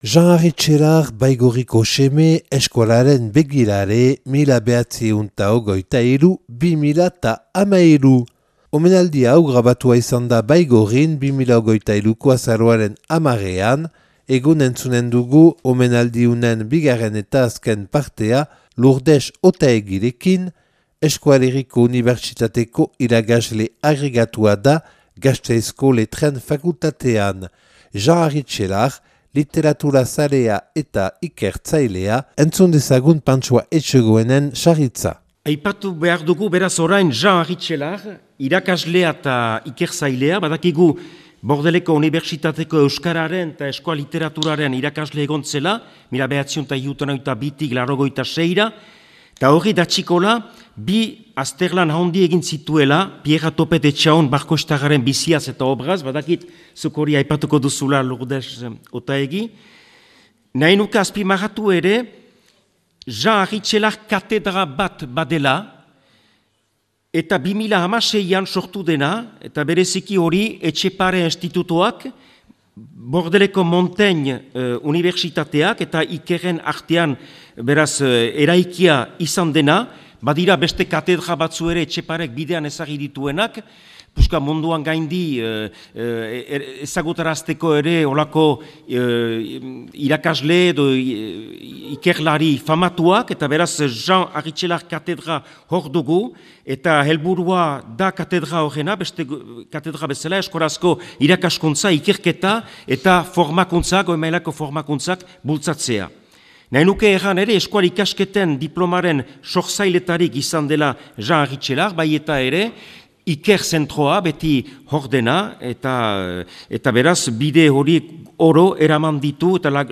(Baigorrin grabatua 2023. Azaroaren 10an.